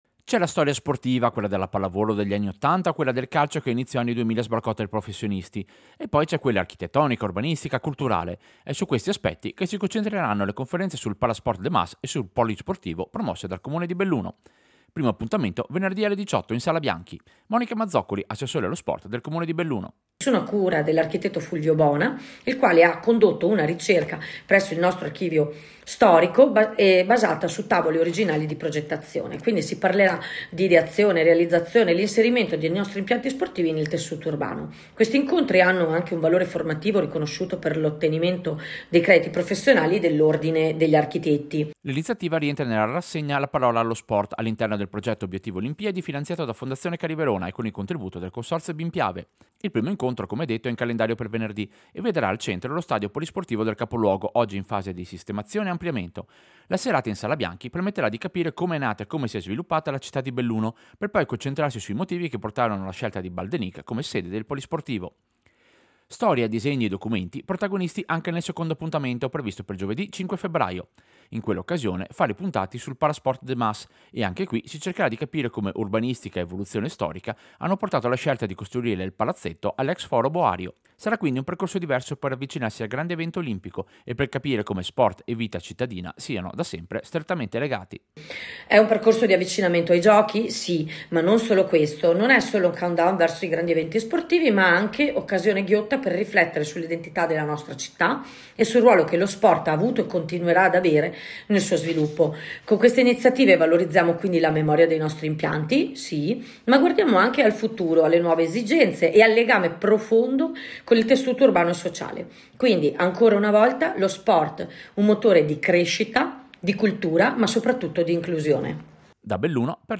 Servizio-Incontri-storia-impianti-sportivi.mp3